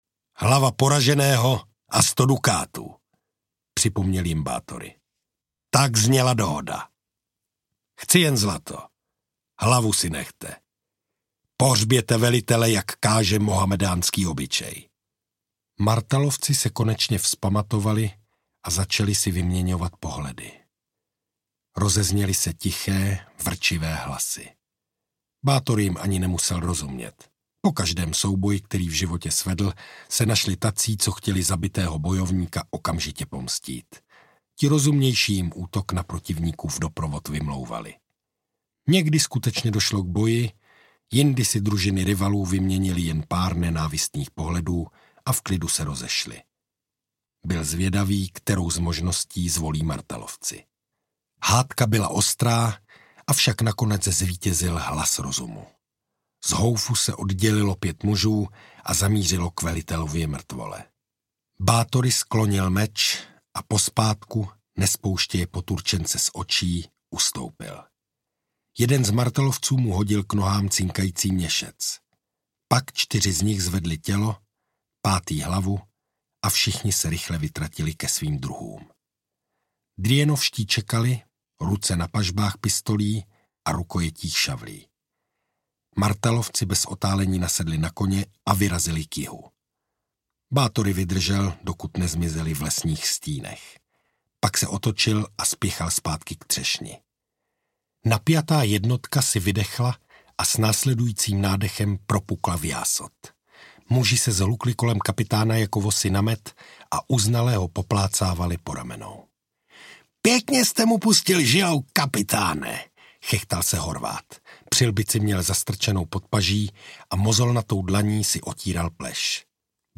Strážcové Varadínu audiokniha
Ukázka z knihy
strazcove-varadinu-audiokniha